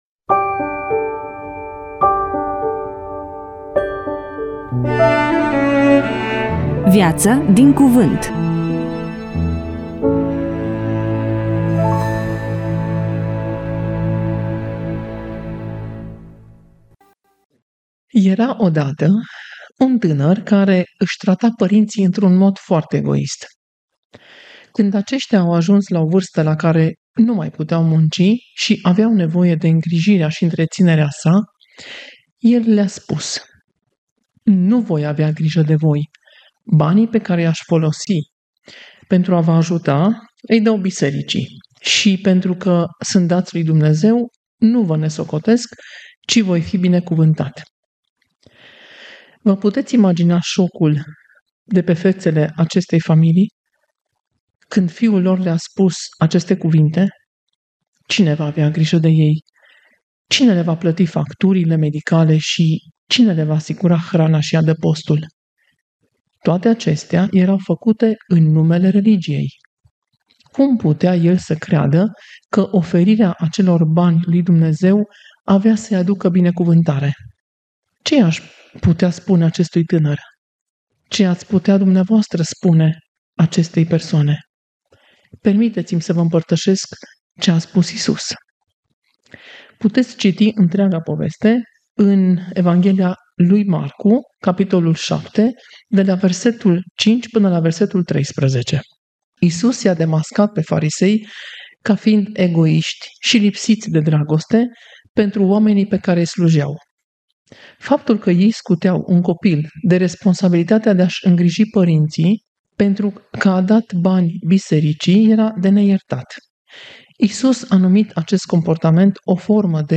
EMISIUNEA: Predică DATA INREGISTRARII: 29.11.2025 VIZUALIZARI: 6